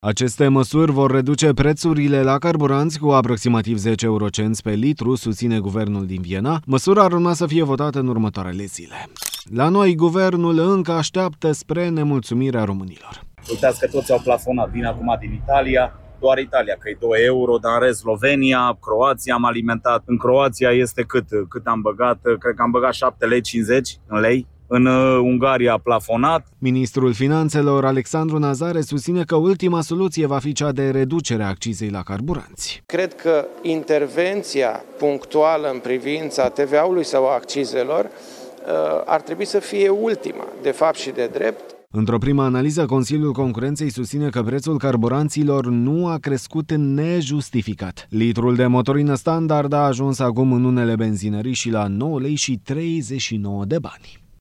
„Uitați că toți au plafonat”, spune un bărbat